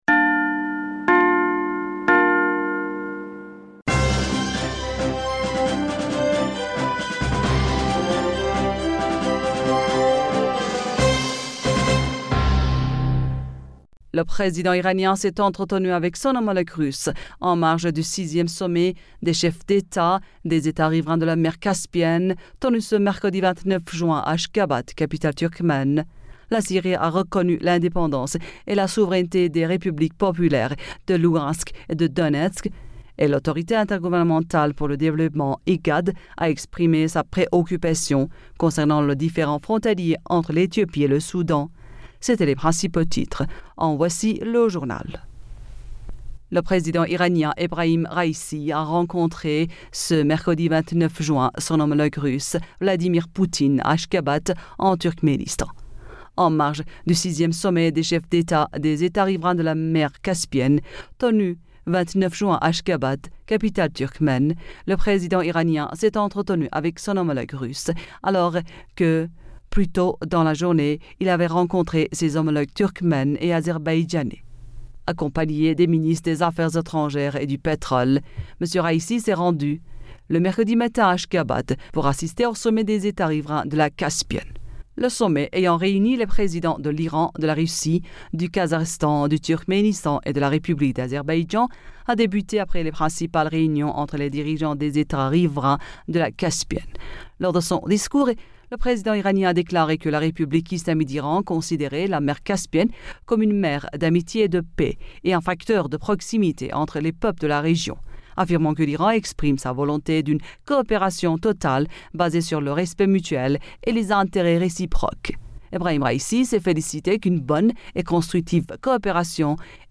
Bulletin d'information Du 30 Juin